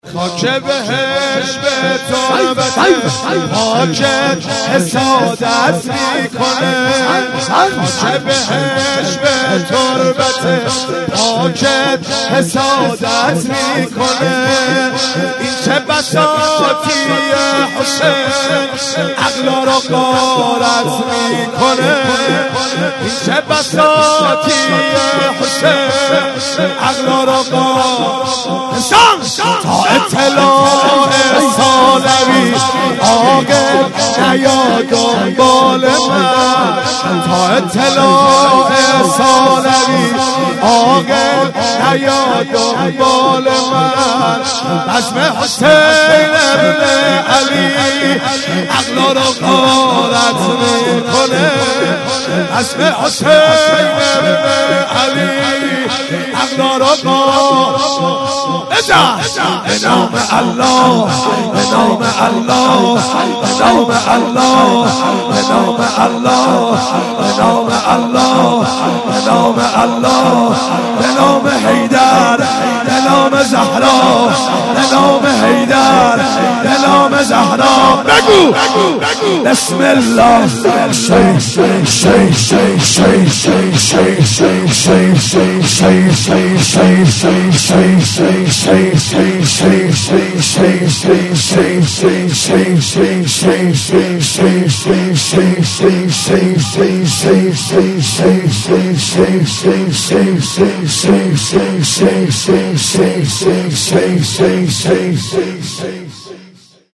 4- خاک بهشته تربتت - شور